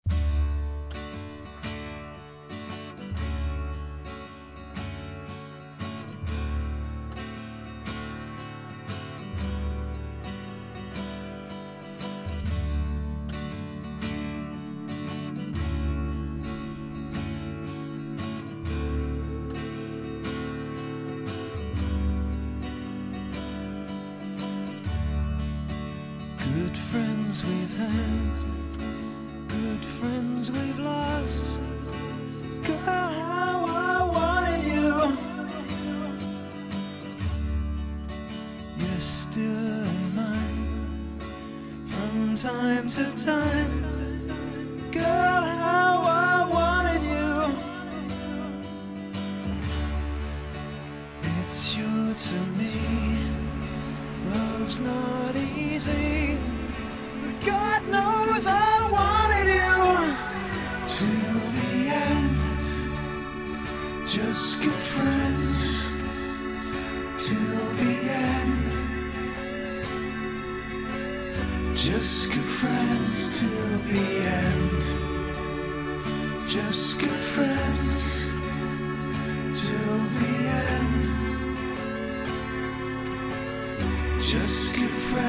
This has to be the most beautiful song I have ever heard.